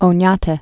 (ō-nyätĕ), Juan de 1550?-1630?